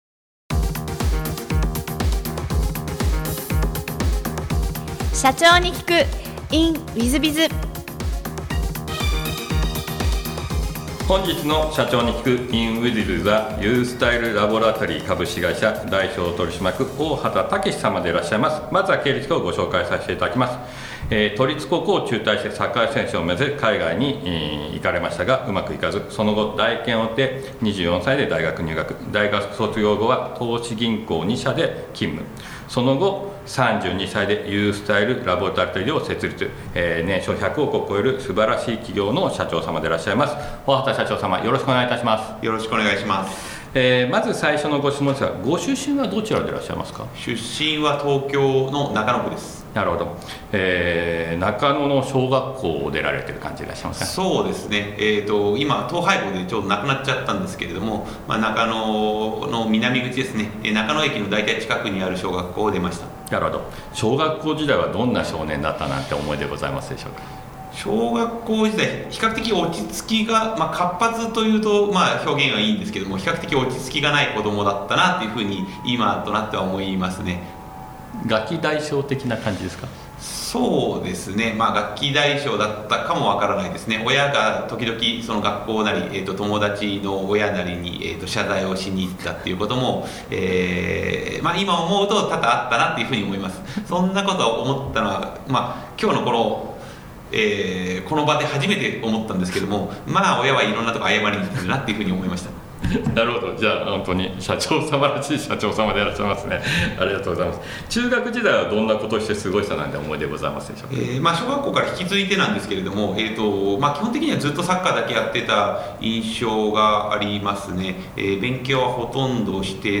訪問介護ビジネスのフランチャイズを展開し、年商100億円の企業へと成長させたエピソードから、経営のヒントが得られます。ぜひ、インタビューをお聞きください。